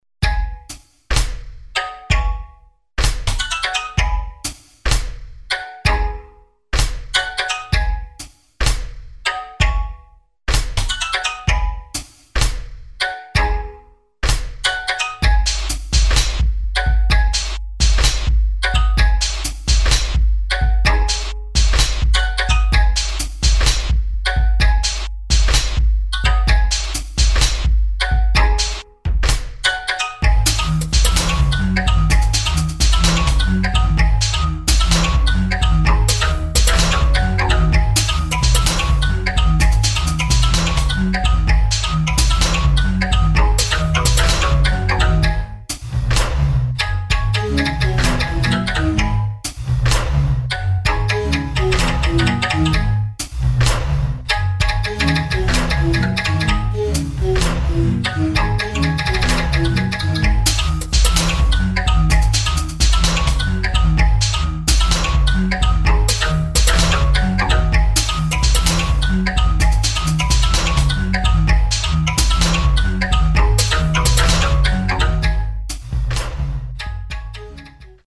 [ DUBSTEP | BASS | EXPERIMENTAL ]